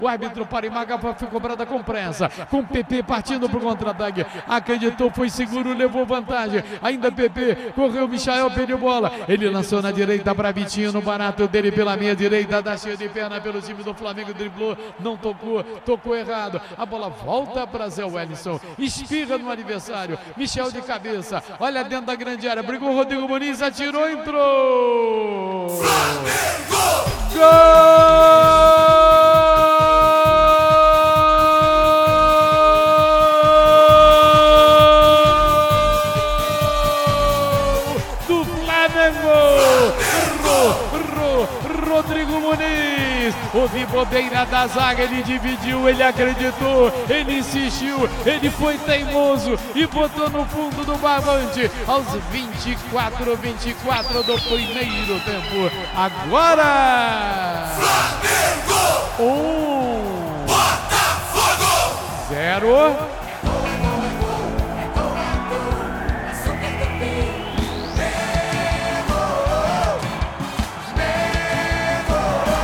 Ouça os gols da vitória do Flamengo sobre o Botafogo com a narração de José Carlos Araújo